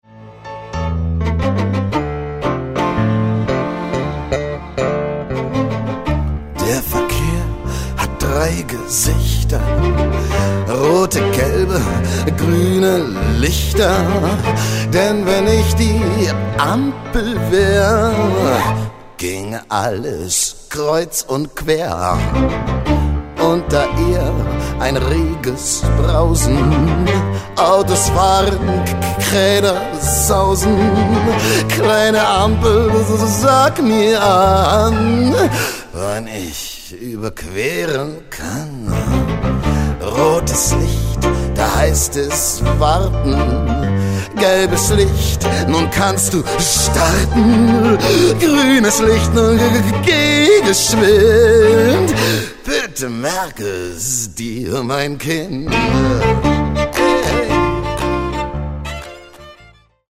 Chansons